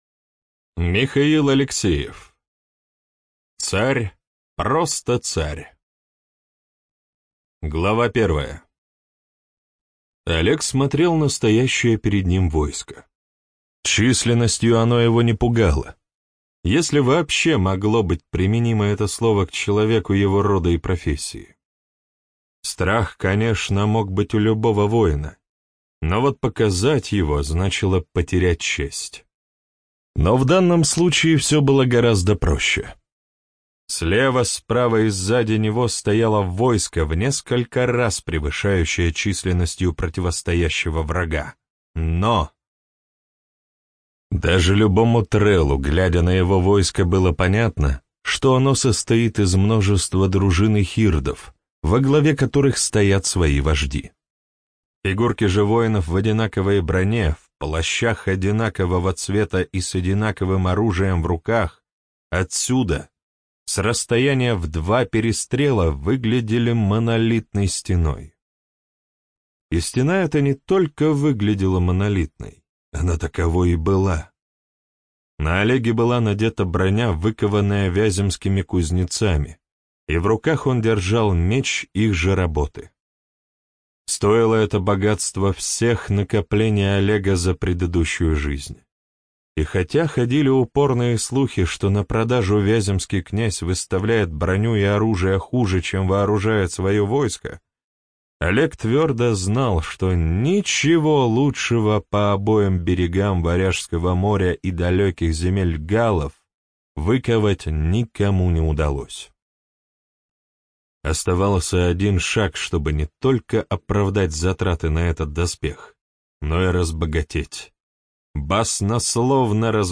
ЖанрФантастика, Альтернативная история